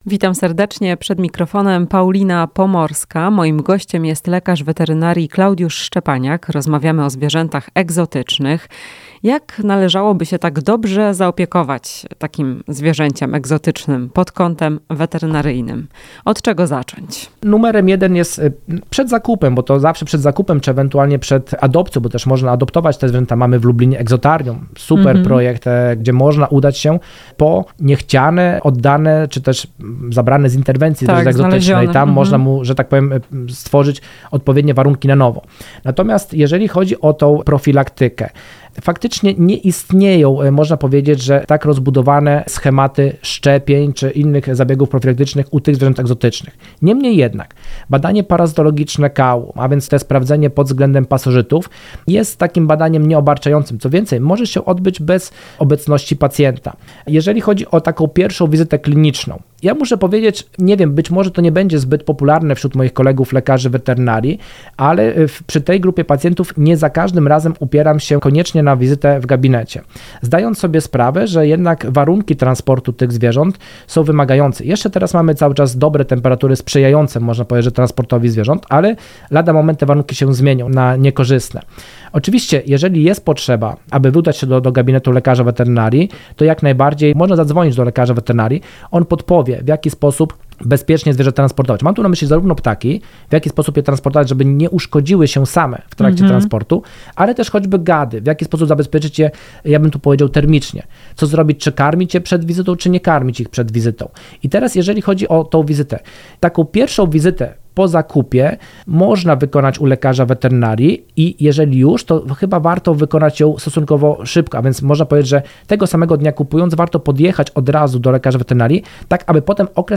W "Chwili dla pupila" porozmawiamy o profilaktyce chorób zwierząt egzotycznych. Rozmowa z lek. wet.